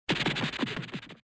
Sonido FX 20 de 42